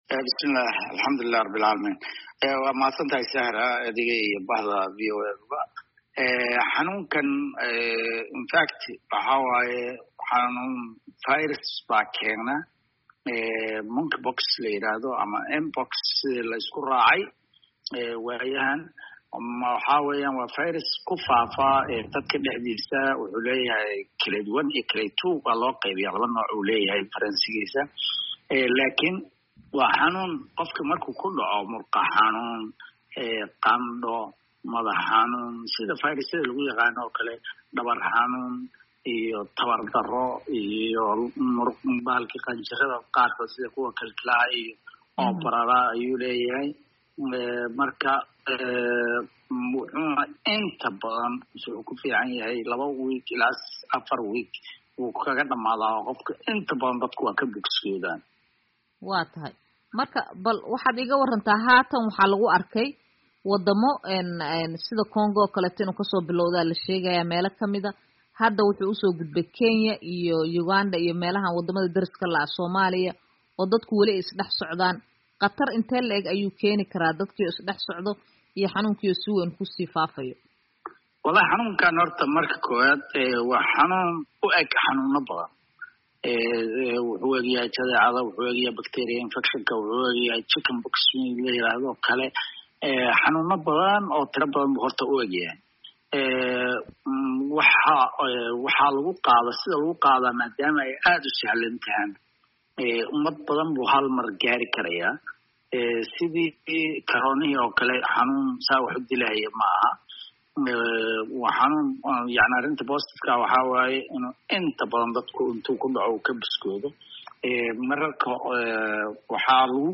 Waraysiga xanuunka Busbuska